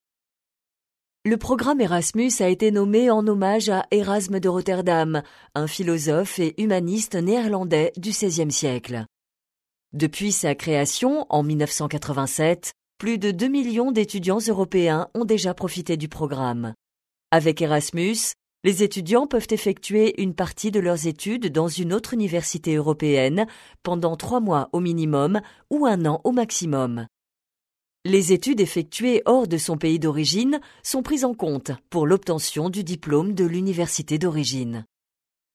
Sprecherin französisch (Muttersprachlerin) warm, smoth and secure, serious, friendly, smilee, fresh intentions
Sprechprobe: eLearning (Muttersprache):
Professional French native voice over: warm, smoth and secure, serious, friendly, smilee, fresh intentions